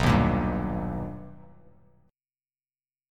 B+M7 chord